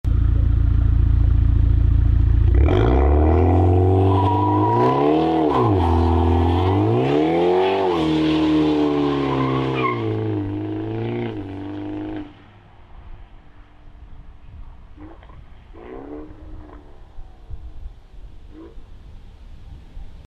Toyota Supra Sound Effects Free Download